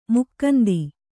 ♪ mukkandi